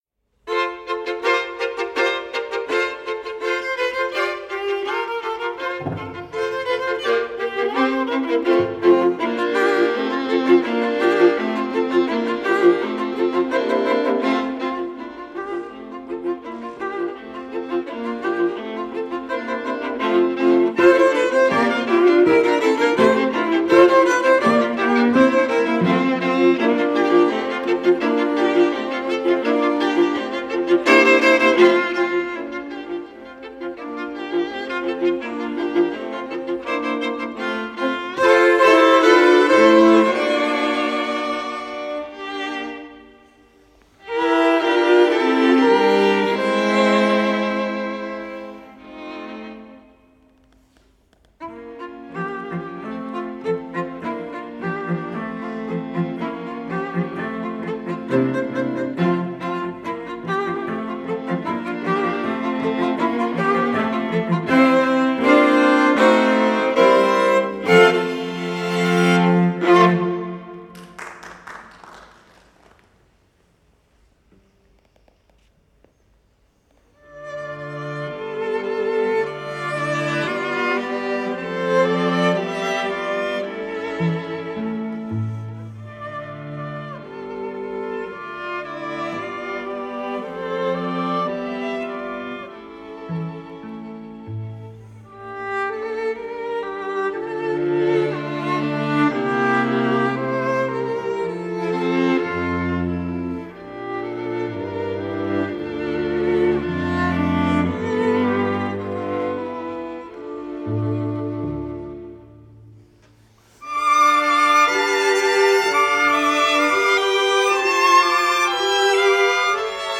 prvé husle
druhé husle
viola
violončelo